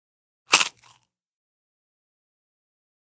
EatCrispHardCrunch PE678005
DINING - KITCHENS & EATING CRISPY SNACK: INT: Single hard crunch.